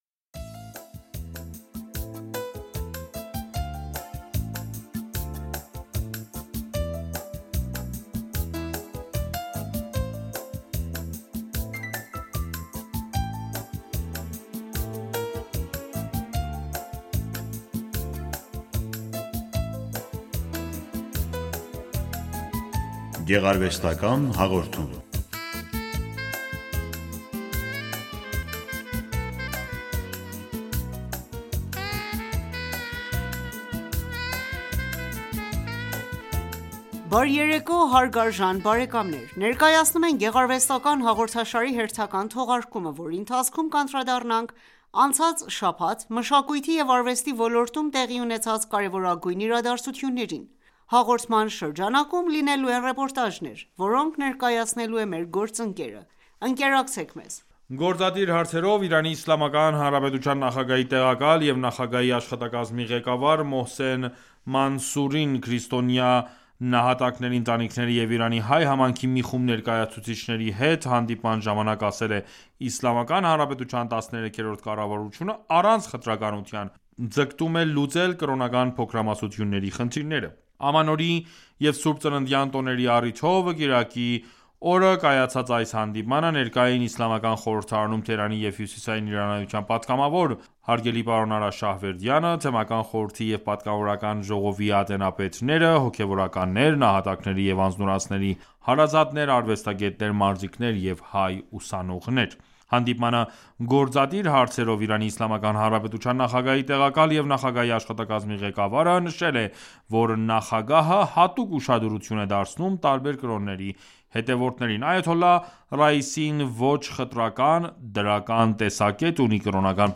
Հաղորդման շրջանակում լինելու են ռեպորտաժներ,որոնք ներկայացնելու է մեր գործընկերը։